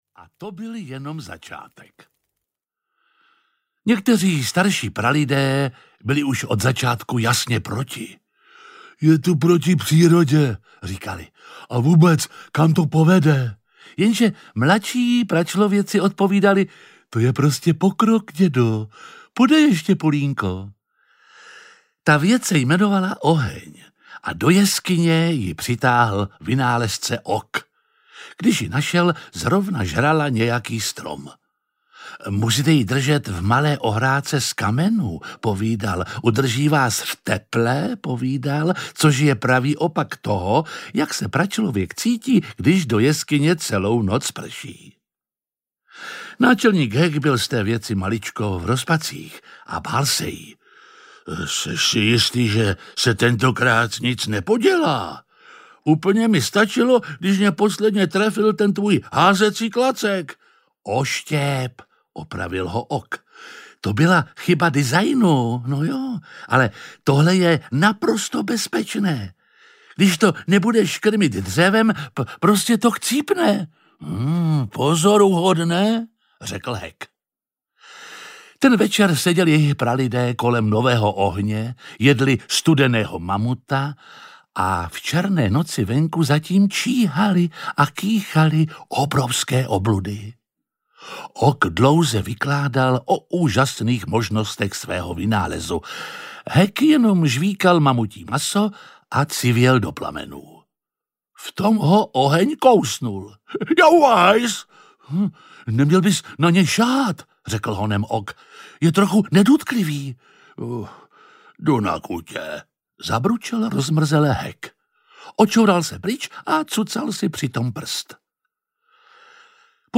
Jedním tahem pera audiokniha
Ukázka z knihy
• InterpretJiří Lábus